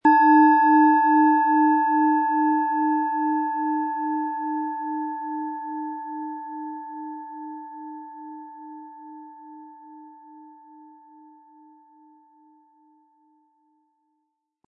Tibetische Universal-Klangschale, Ø 9,9 cm, 180-260 Gramm, mit Klöppel
Wohltuende Klänge bekommen Sie aus dieser Schale, wenn Sie sie mit dem kostenlosen Klöppel sanft anspielen.
SchalenformOrissa
MaterialBronze